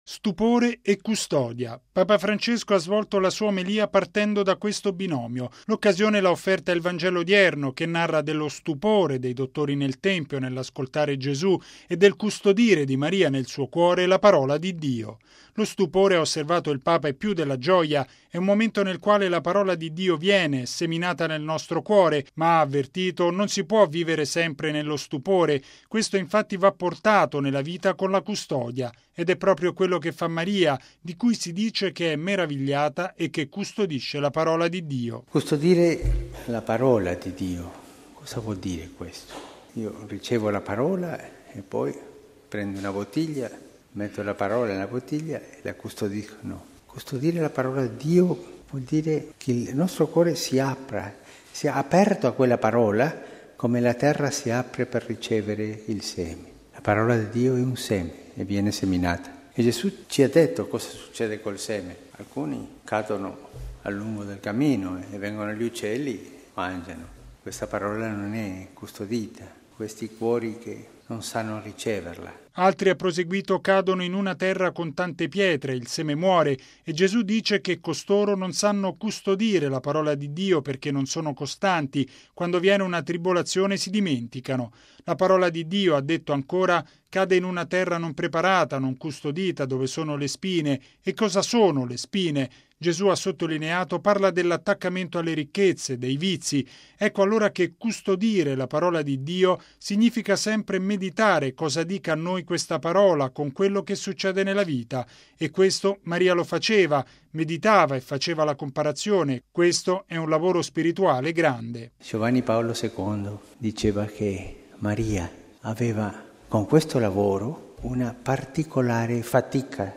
E’ quanto affermato da Papa Francesco nella Messa alla Casa Santa Marta, nell’odierna memoria del Cuore Immacolato della Beata Vergine Maria.